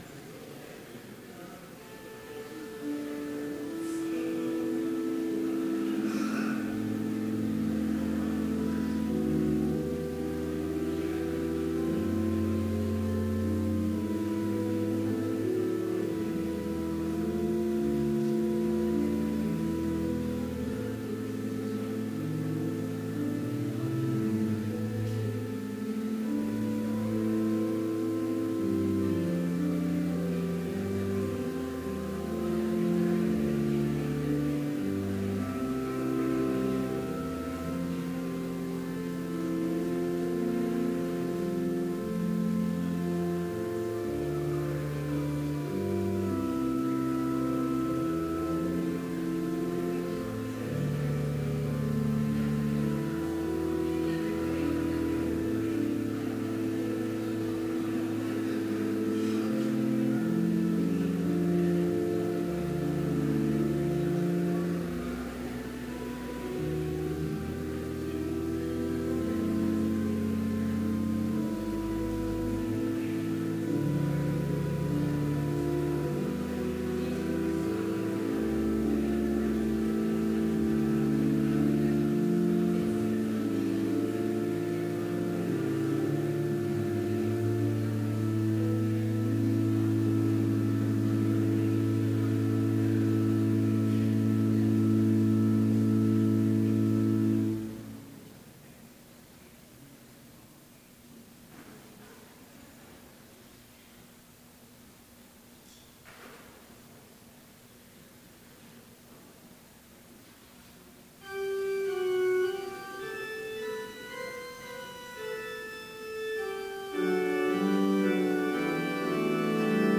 Complete service audio for Chapel - December 8, 2016